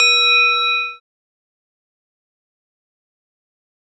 metal thud
ding metal sheet thud tin sound effect free sound royalty free Sound Effects